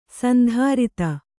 ♪ sandhārita